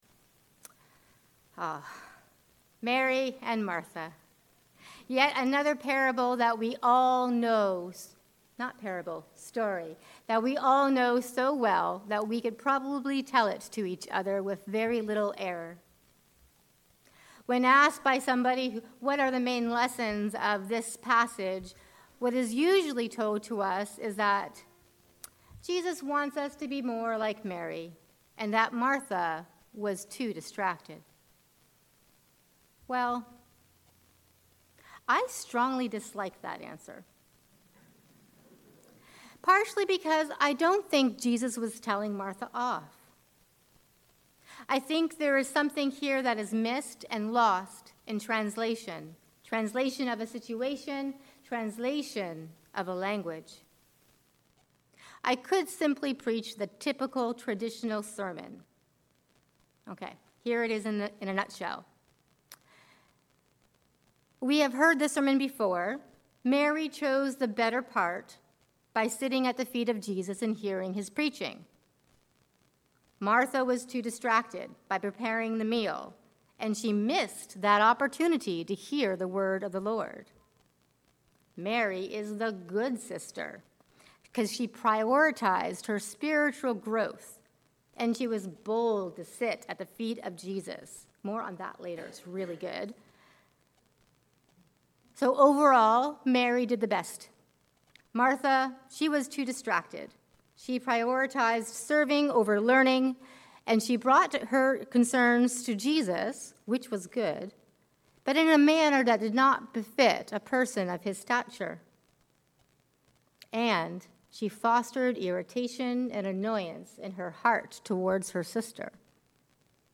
Making demands of Jesus. A sermon on Martha and Mary